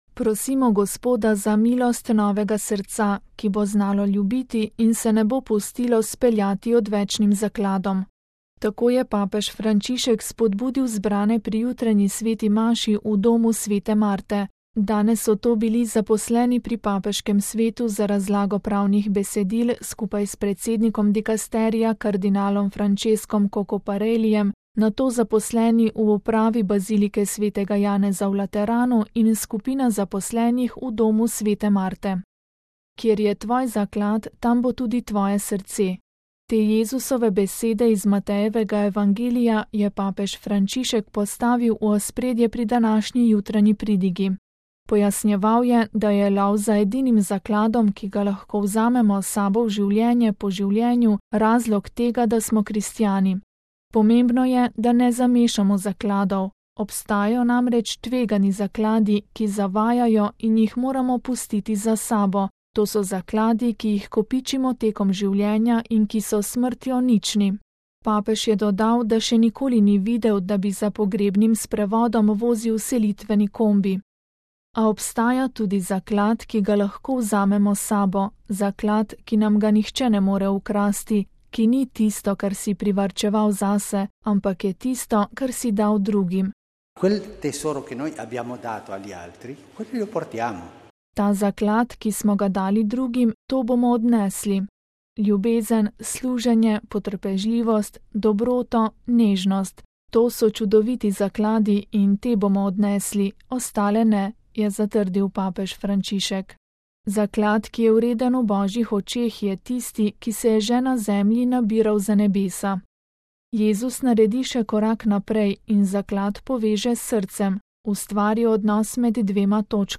Papež Frančišek med jutranjo sveto mašo: Gospod naj nam spremeni srce, da bomo postali svetle osebe
Tako je papež Frančišek spodbudil zbrane pri jutranji sveti maši v Domu sv. Marte.